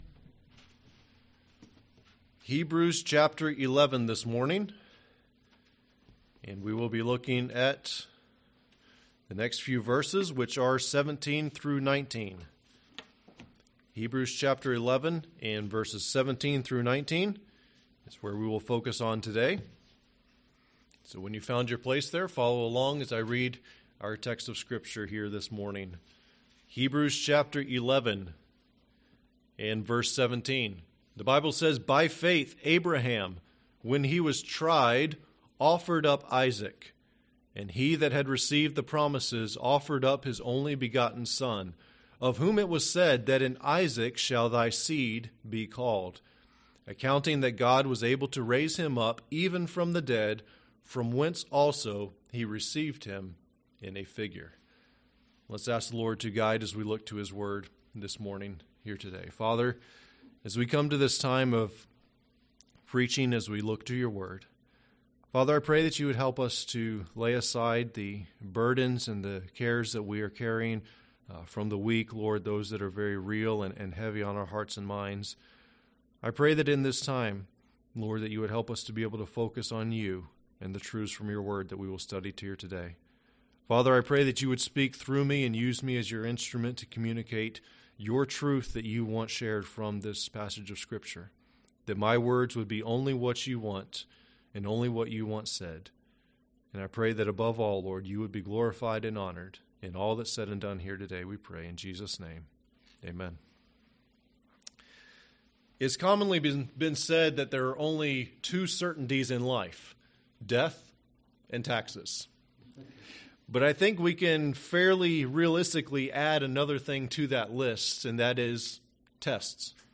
In this message from Hebrews 11:17-19, we learn from the life of Abraham what it means to fully surrender everything to God.